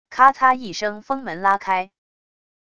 咔擦一声风门拉开wav音频